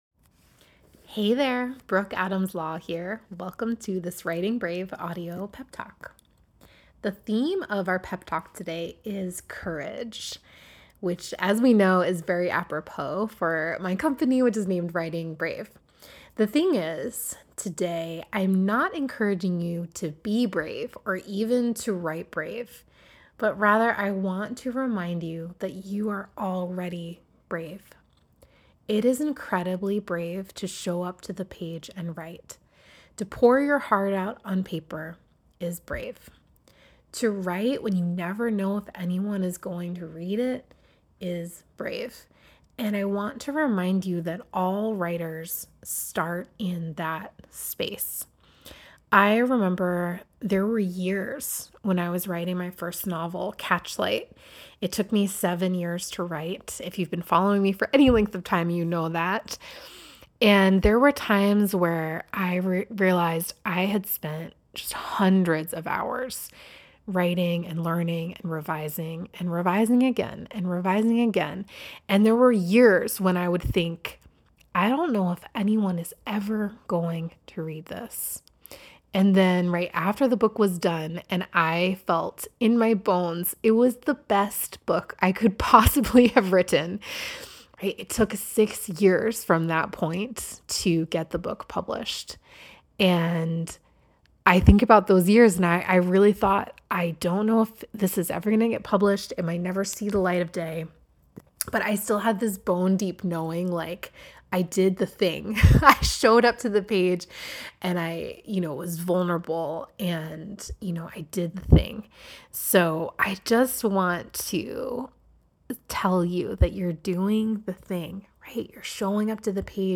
Each audio pep talk is less than five minutes, and they’re like having a pocket cheerleader for your writing (me!).